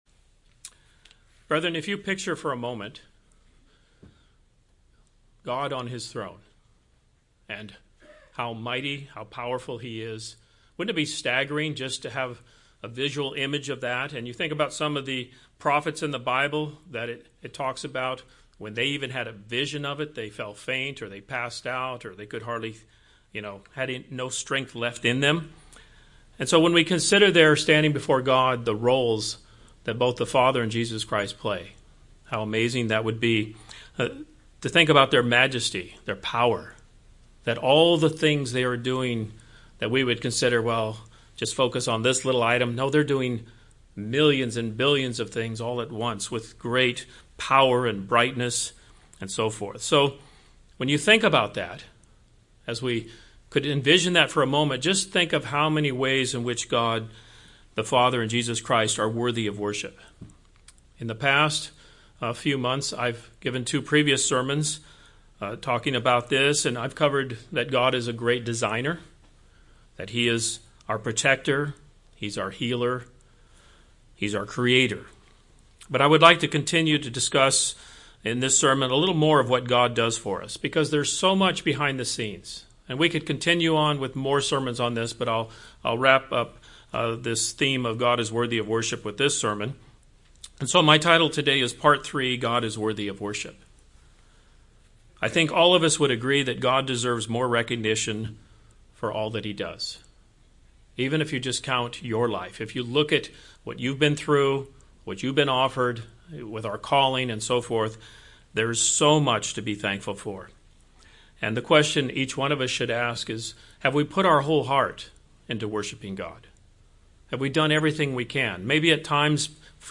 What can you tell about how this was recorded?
Given in St. Petersburg, FL